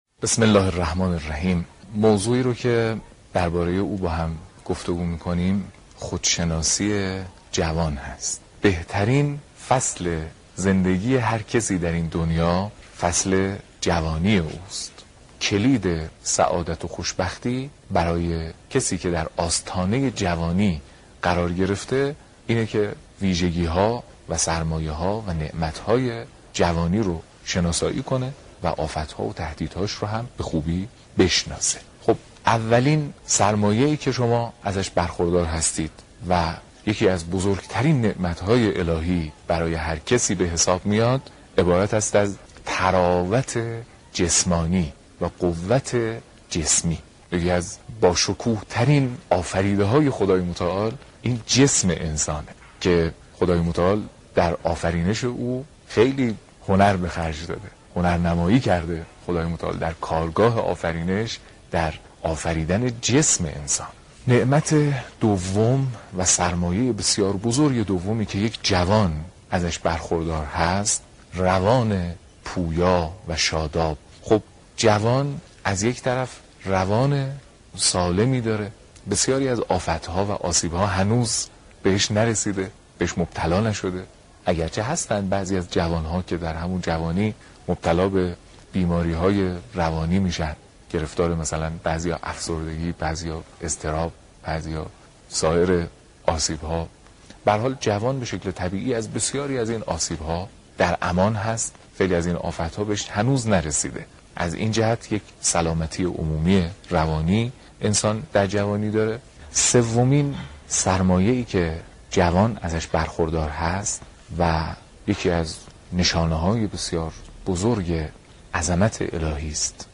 سخنرانی حجت الاسلام والمسلمین محمدجواد حاج علی اکبری